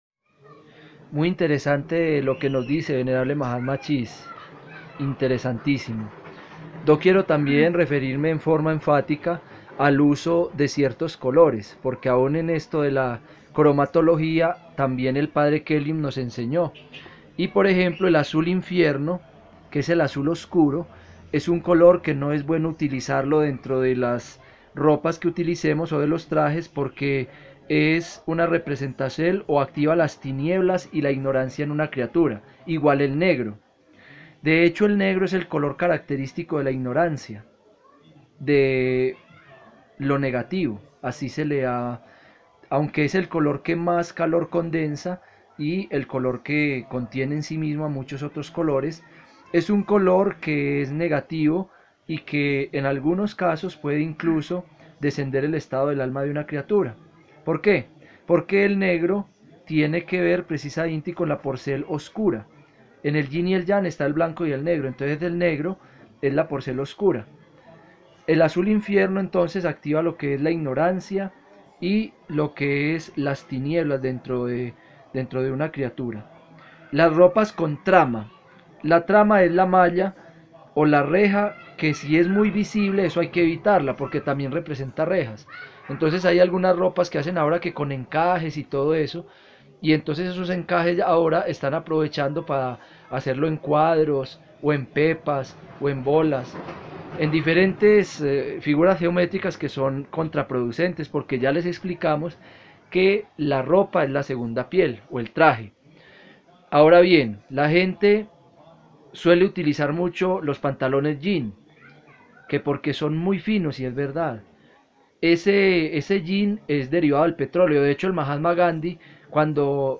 Conferencia sobre las ropas viles